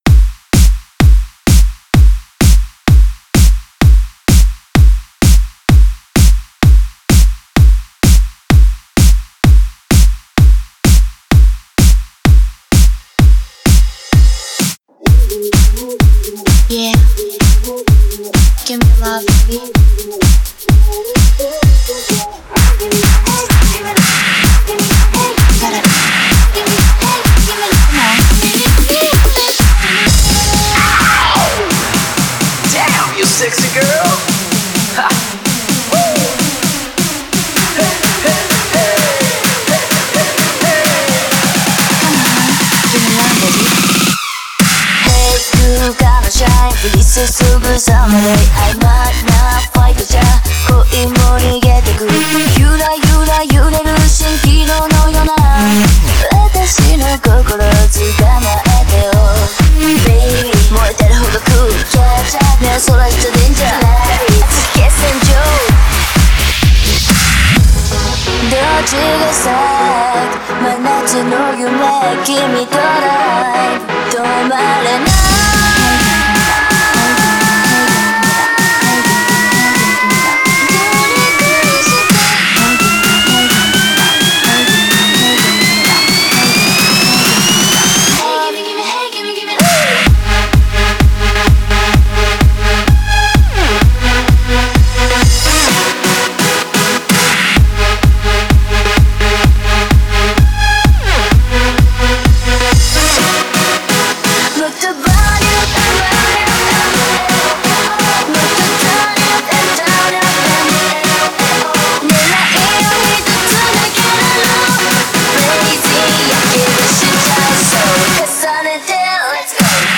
Genre: Electro-House/Big Room
BPM : 128